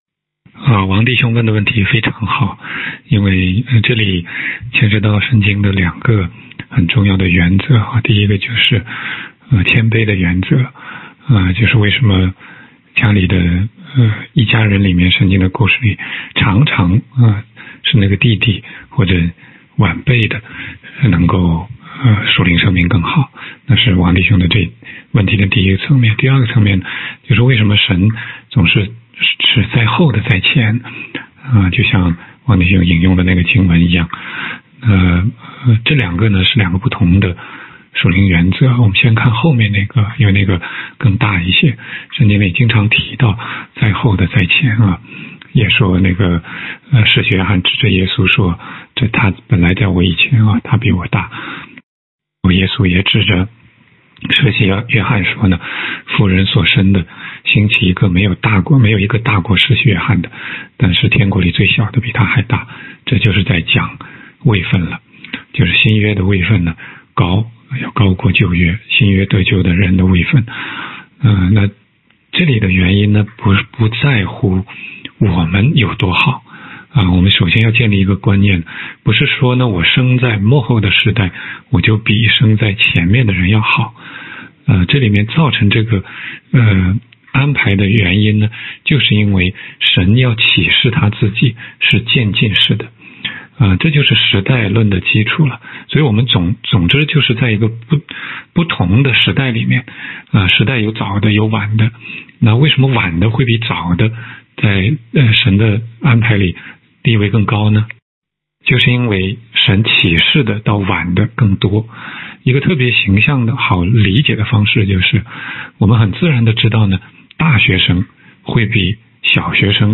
16街讲道录音 - 圣经中经常是后来的反而走在先来的前面，年幼的反而比年长的为大, 神的这种安排有何奥义?